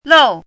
chinese-voice - 汉字语音库
lou4.mp3